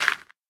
gravel2.ogg